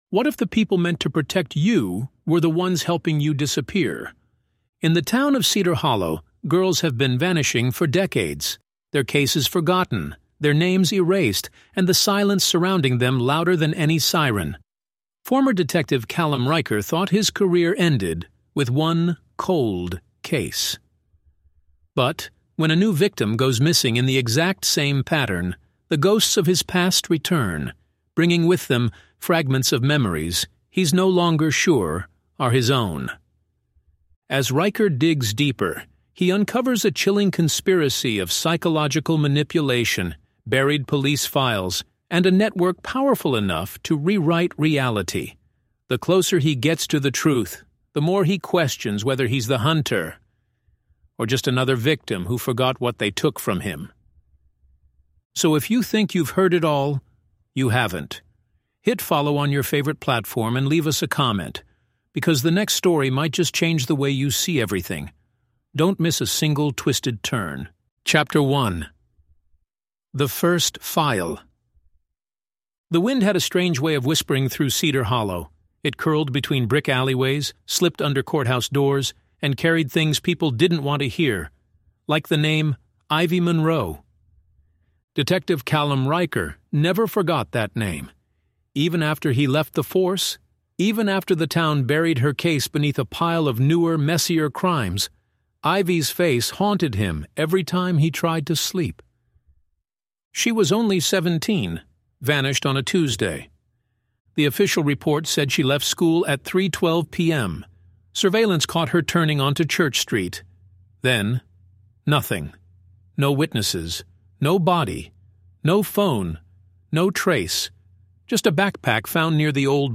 When Justice Fails: The Unsolved Cases is a gripping cinematic true crime fiction series that delves into the unsolved mysteries, corruption, and hidden narratives buried beneath a town’s shattered justice system. Follow ex-detective Callum Ryker as he reopens cold cases long buried by conspiracy, institutional scandal, and the eerie manipulation of false memories.